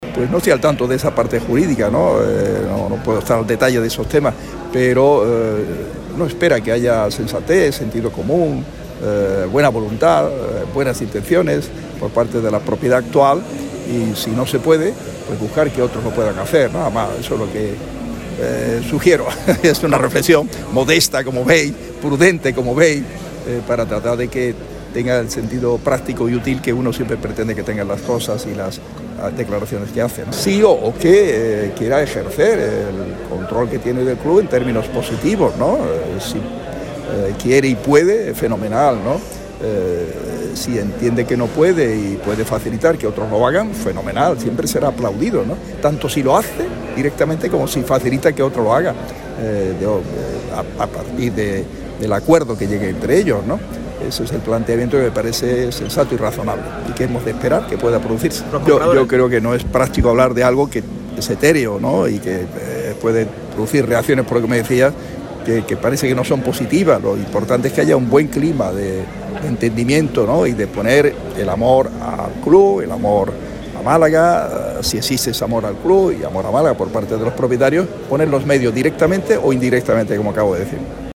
Este jueves, Francisco de la Torre compareció ante los medios de comunicación tras acudir a la presentación oficial del II Meeting Internacional de Atletismo Ciudad de Málaga. Allí atendió a Radio MARCA Málaga y Cadena SER donde habló de temas relacionados con la actualidad del Málaga Entre ellos, el asunto de la remota venta del Málaga así como del bloqueo judicial en el caso Al-Thani.